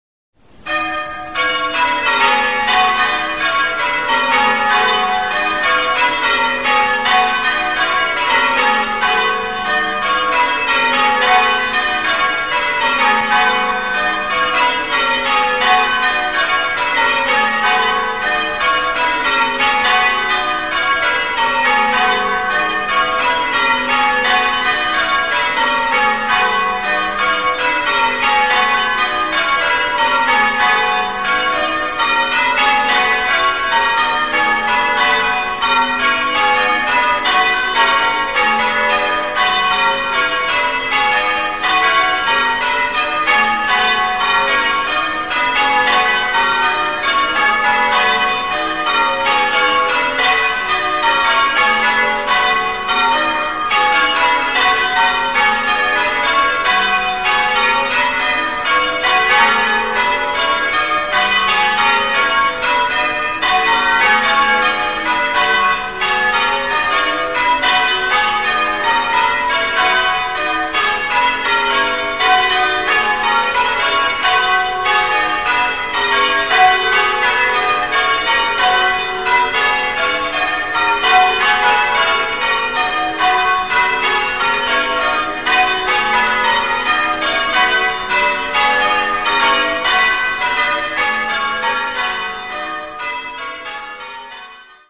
Suckley church bells
Extract 1 (starting the St Simon's Doubles method)
They were recorded at the weekly Wednesday practice at about 9pm on 7 February 2001, from the middle of the churchyard, by the light of a full moon!
bells1.mp3